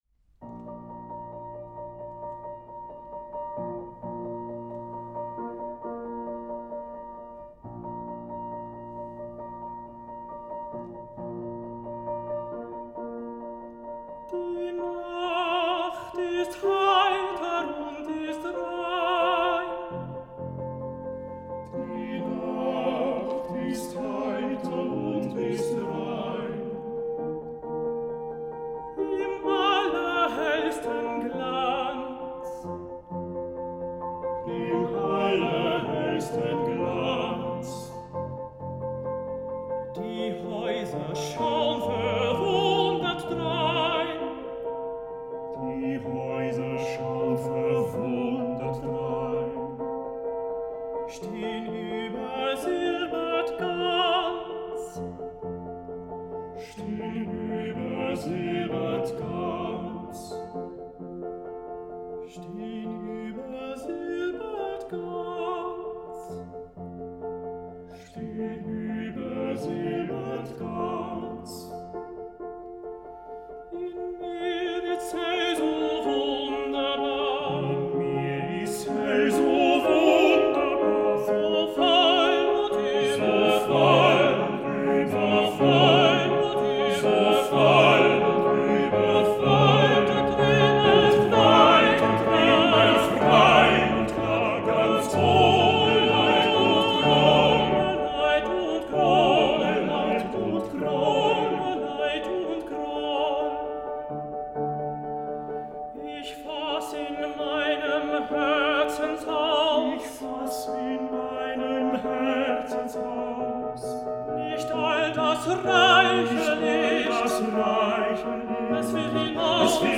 Five classically trained soloists and the pianist
The choral pieces in the program are generally performed with one voice per vocal line as was standard practice in the time of Franz Schubert. The goal is to create a homogeneous ensemble sound with each singer still displaying his individual vocal qualities. This gives VirCanto its unique full-bodied sound as well as its dynamic flexibility.
Songs of the German Romantic Period